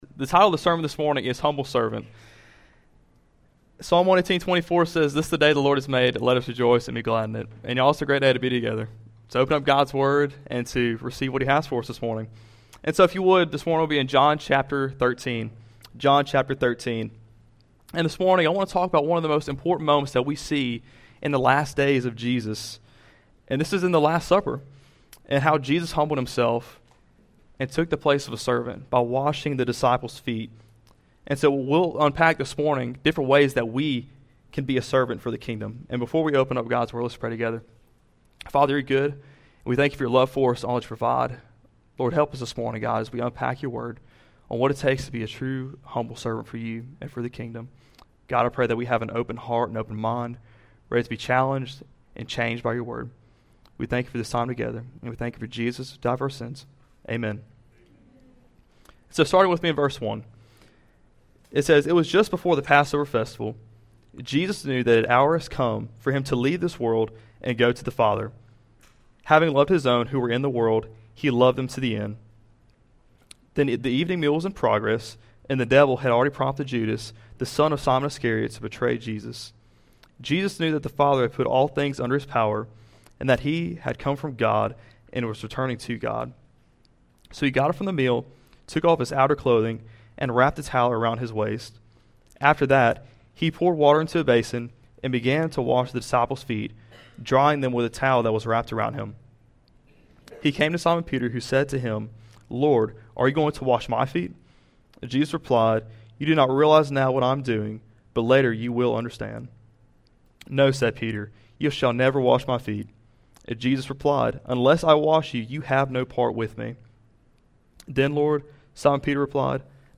Stand-Alone Sermons